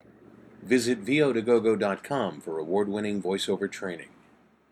visit-no-normalization.mp3